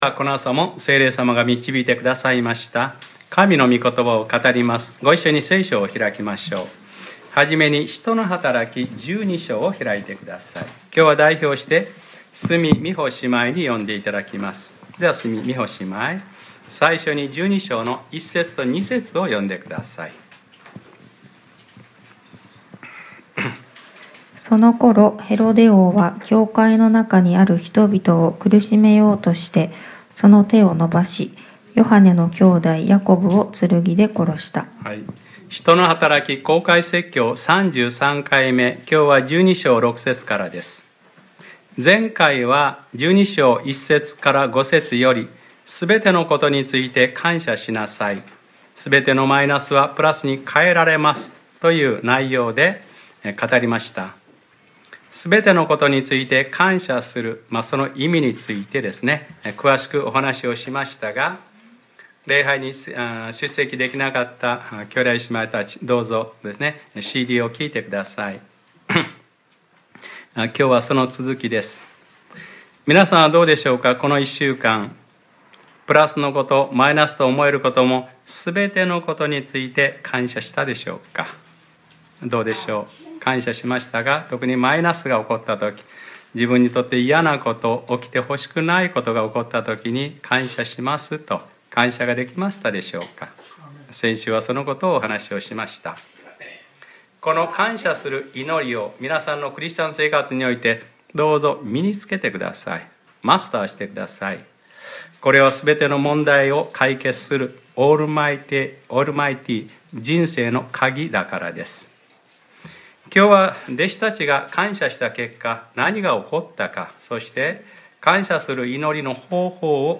2019年03月03日（日）礼拝説教『使徒ｰ33:すべてのことについて感謝しなさい』 | クライストチャーチ久留米教会